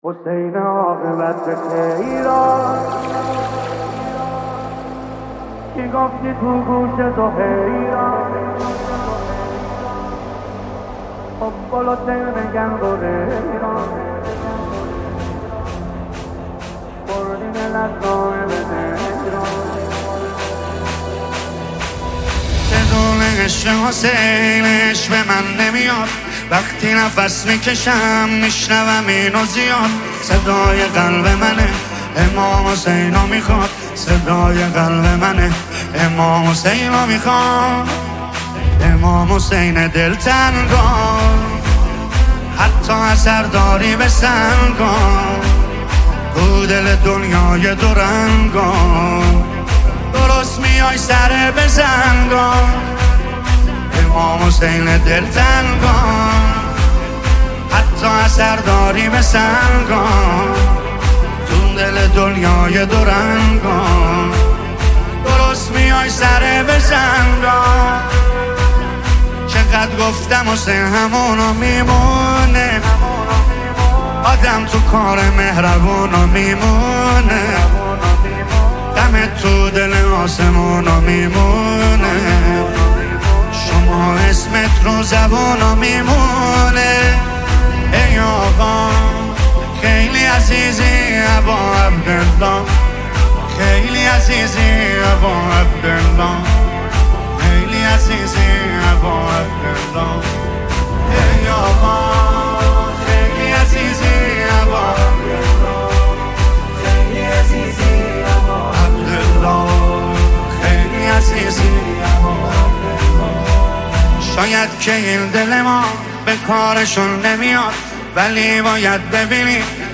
مداحی ماه محرم
مداحی امام حسین